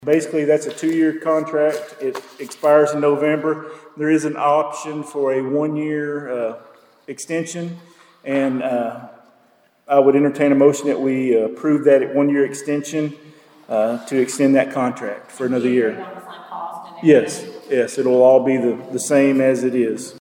The Princeton City Council received an update on a proposed street acceptance request and approved a one-year extension for a burial site preparation contract at Monday night’s meeting.